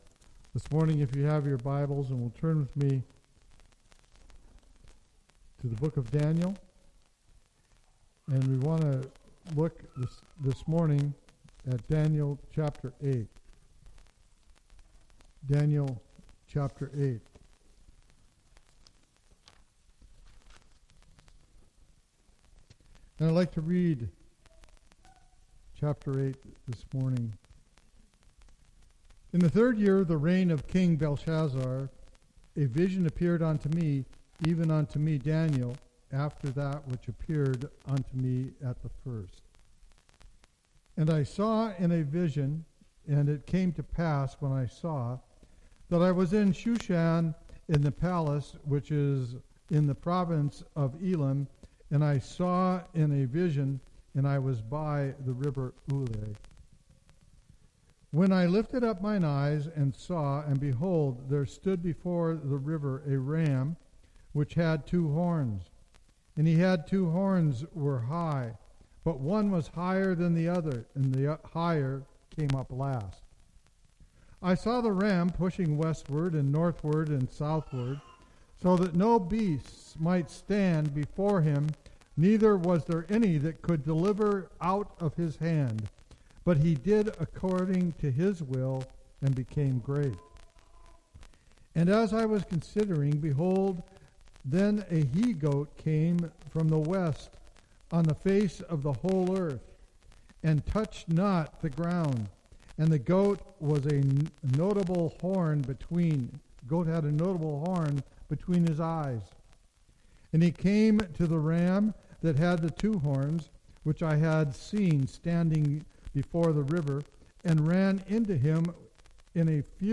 Explore Daniel 8’s prophetic vision of the ram, the goat, and the little horn. This sermon uncovers the historical fulfillment, spiritual warfare, and end-time patterns revealed in this powerful chapter.